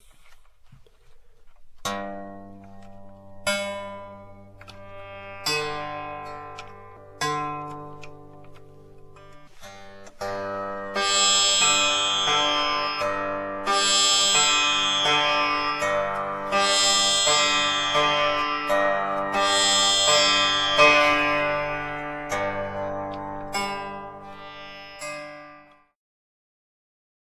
L’exemple sonore suivant permet d’entendre le son de la tampura sans et avec le chevalet plat (on a placé son doigt à l’extrémité haute du chevalet pour figurer un chevalet fin en début d’extrait, afin d’entendre la différence).